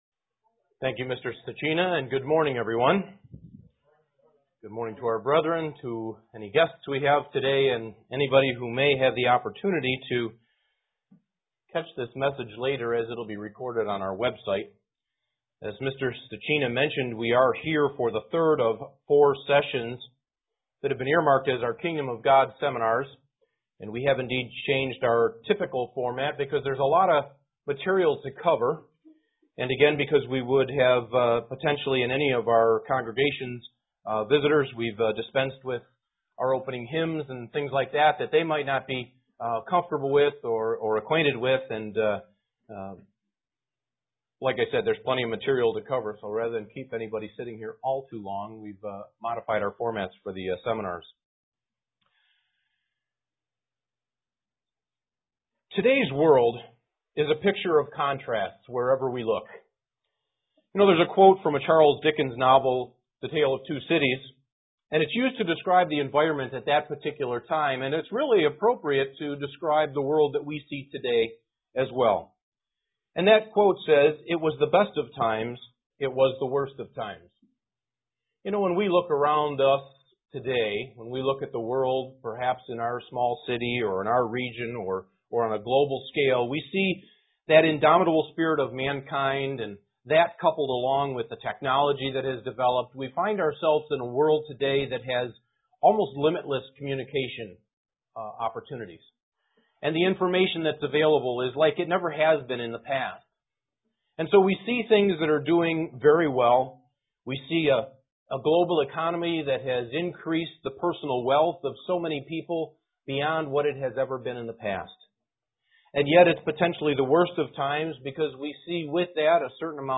Kingdom of God Seminar 3 Lecture 1